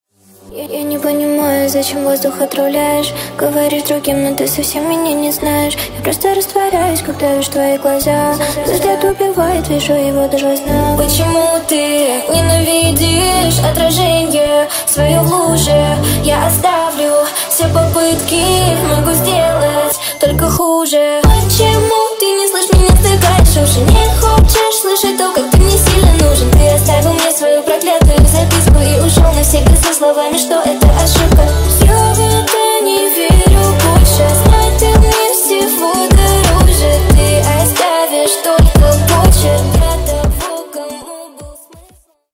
Поп Музыка # спокойные # грустные